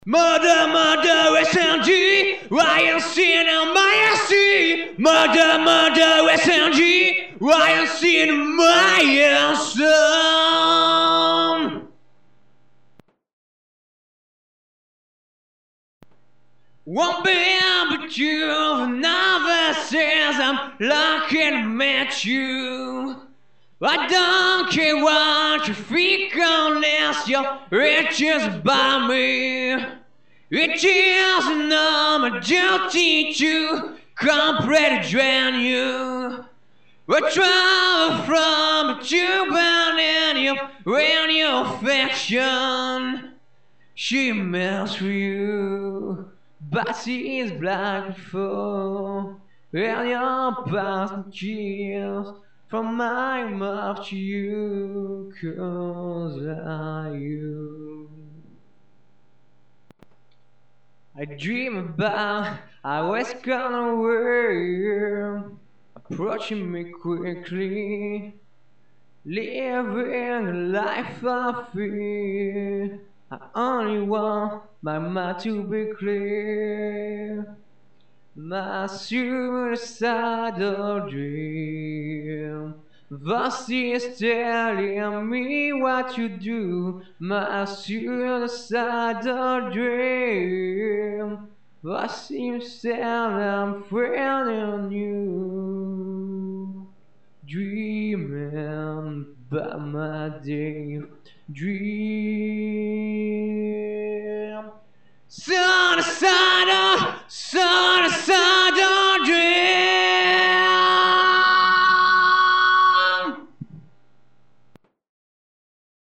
Ca faisait un p'tit moment, et là je viens d'avoir un cable pour m'enregistrer sur mon pc donc j'en ai profiter pour m'amuser, j'ai enregistrer 2/3 trucs à l'arrache avec juste la voix, sans parole, c'est du "yaourt" donc , 2 p'tite compos et un trip (enfin le tout est un trip aussi
(çà fait zarb sans musique derrière,lol)
moi je trouve ça très naturel, ya de l'assurance dans la voix,